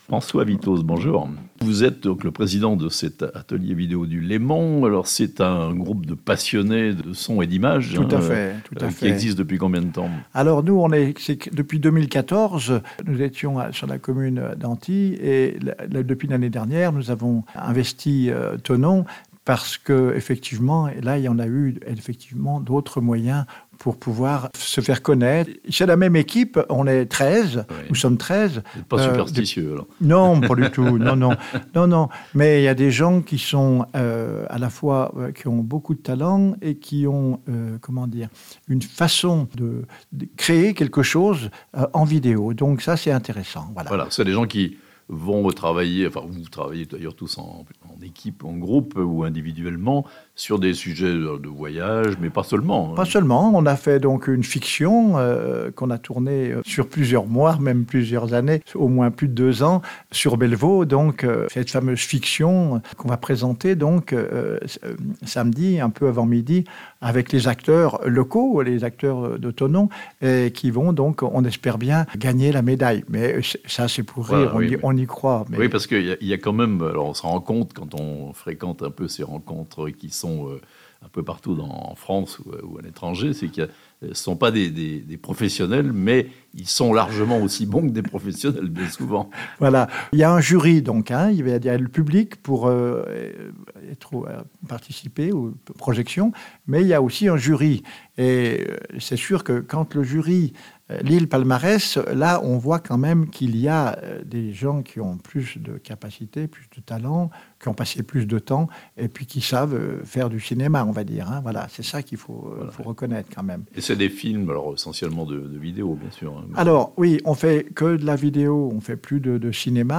Thonon : les 75èmes rencontres Auvergne Rhône-Alpes cinéma/vidéo (interview)